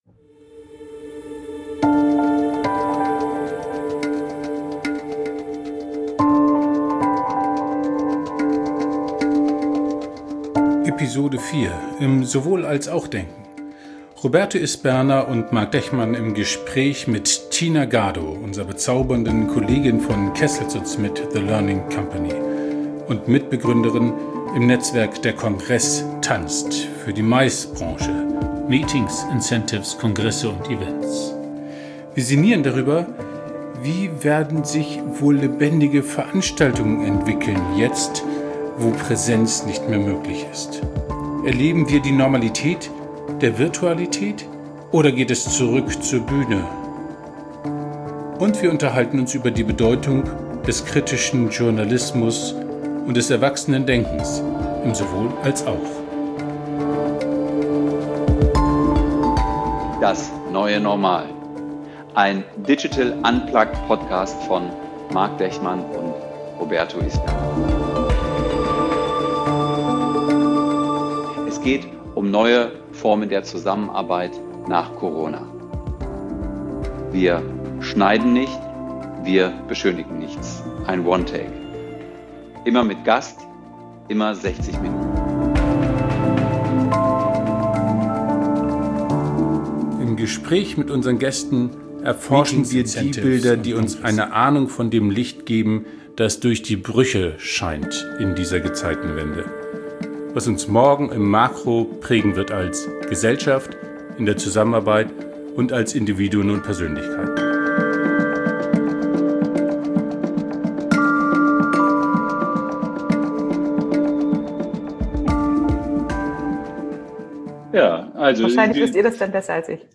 Ein Gespräch unter Freunden.